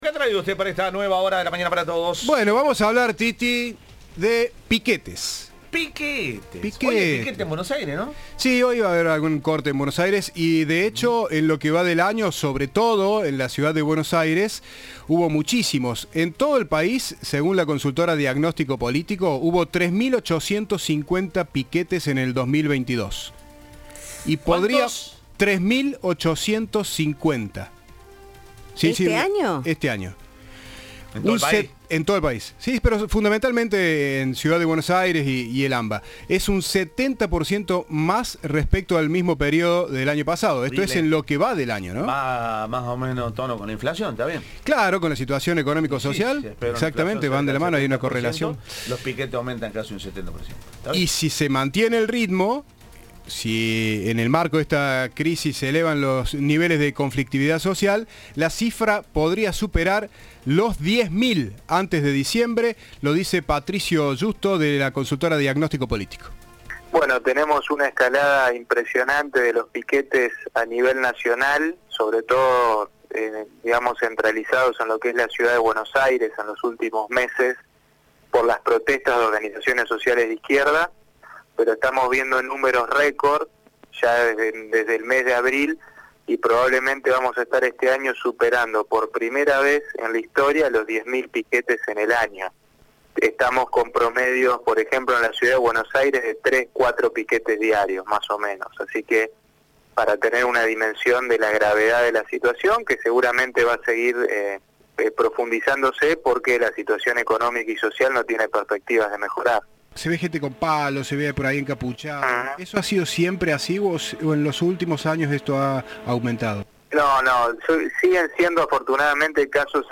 Un analista afirma que en Argentina "hay cultura de piquete"